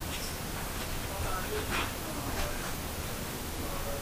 About the clip: One of the most interesting claims of activity in this home is that of the voice (and physical presence) of a little girl spirit. During the "static EVP session", when we set a digital voice recorder in a room and then left the room, we captured the soft, but very clear voice of a little girl! This clip has a lot going on, so listen carefully: You can hear one of the female investigators talking downstairs, and interestingly enough you can also hear a male-sounding hoarse whisper voice speaking a few words. About one second into the clip you can hear the little girl's voice.